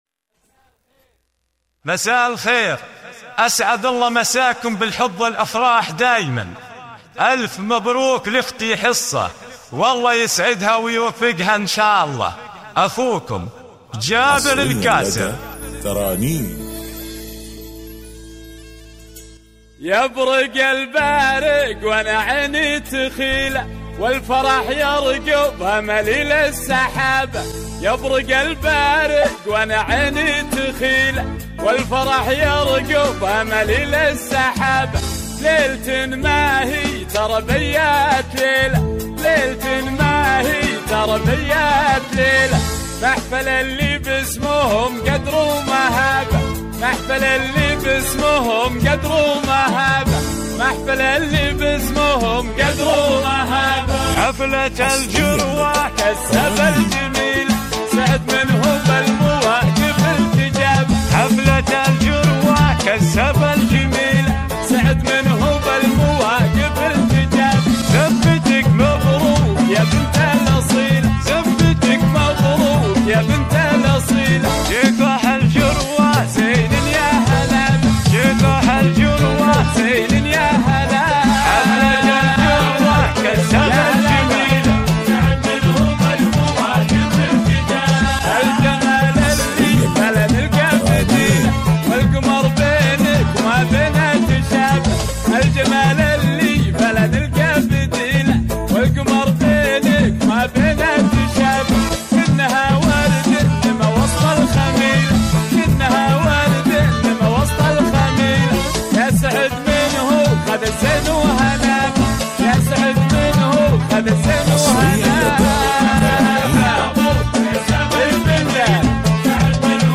بدون موسيقى